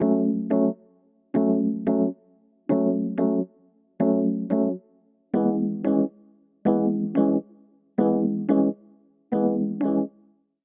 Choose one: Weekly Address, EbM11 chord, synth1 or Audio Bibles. synth1